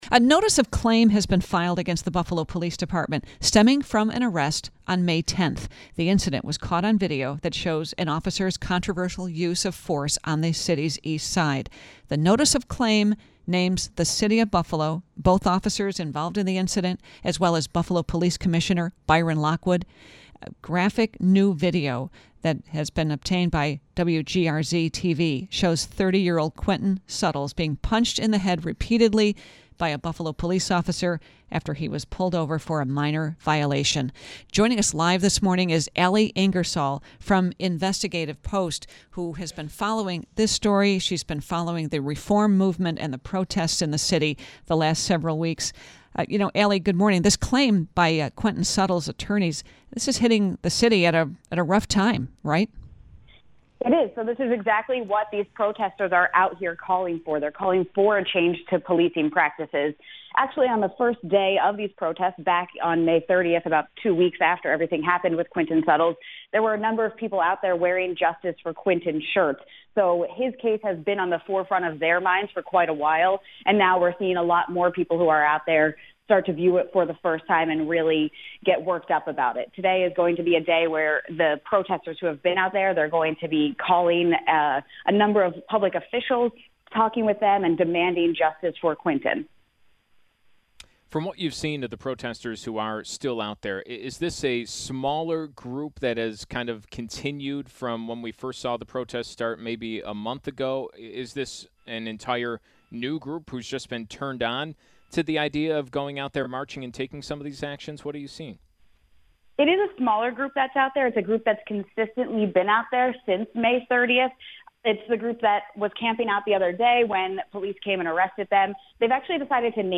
talks police on the radio
WBEN interview